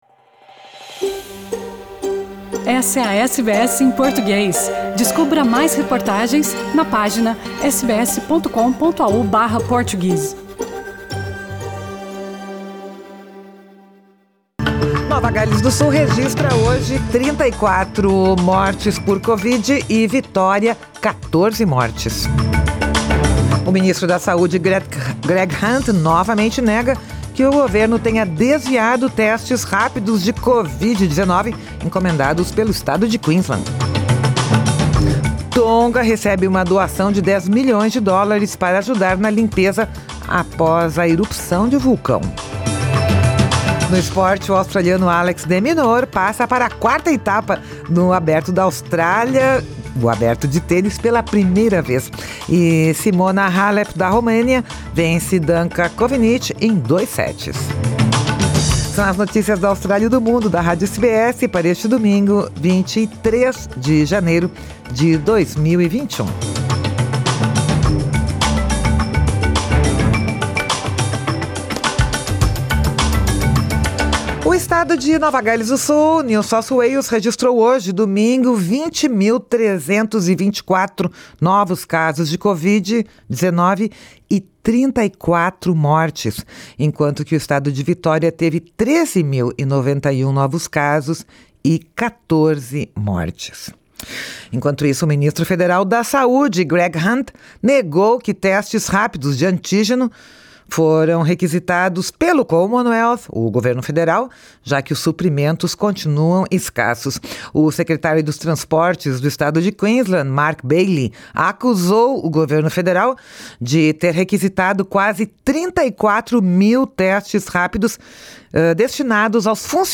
São as notícias da Austrália e do Mundo da Rádio SBS para este domingo, 23 de janeiro de 2021.